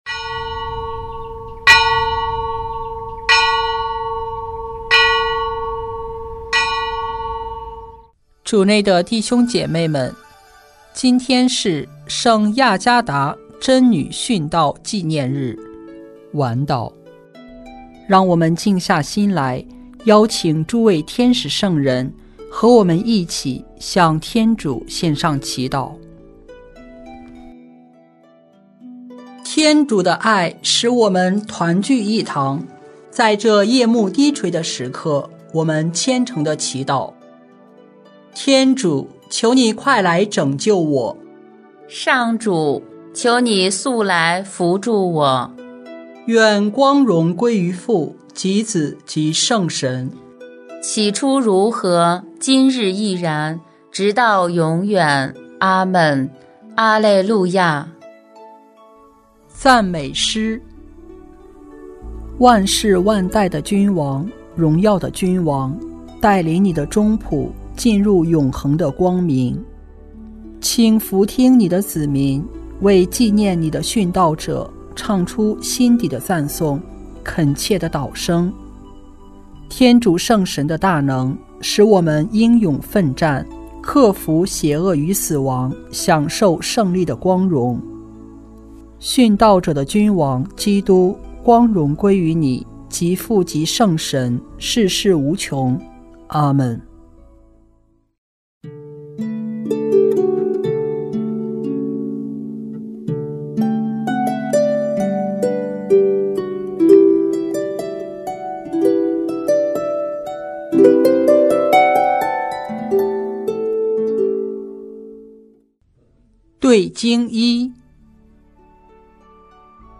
【每日礼赞】|2月5日佳德（亚加大） 贞女殉道纪念日晚祷（第四周周四）